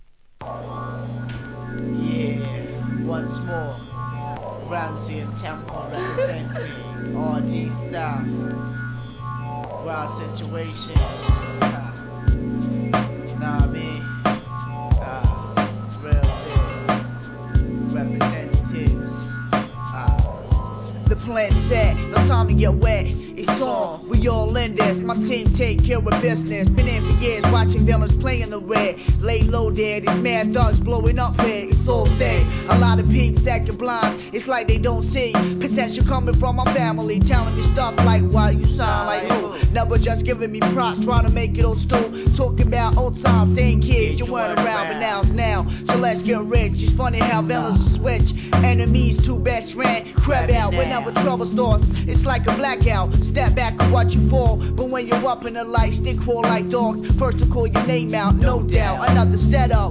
HIP HOP/R&B
UK HIP HOPアンダーグラウンド!!